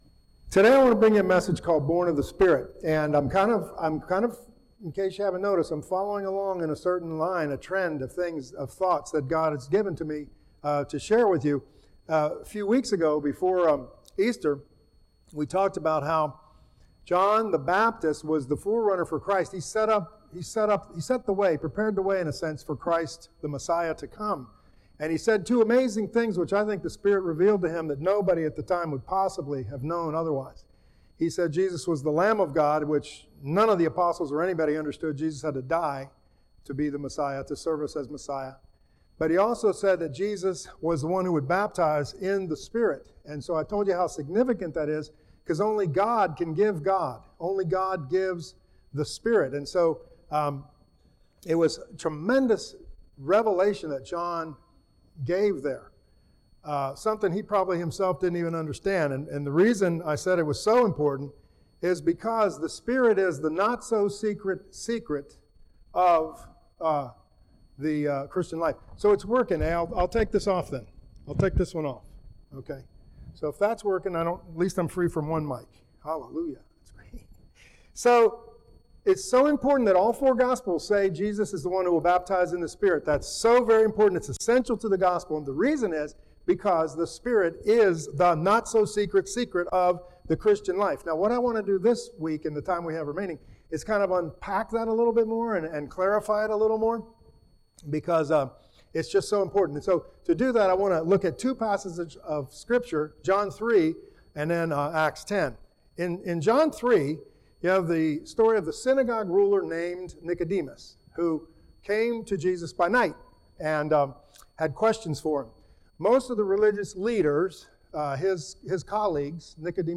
Here you will find English sermons, preached at Beverly Hills Community Church and after leaving that church to serve in other venues.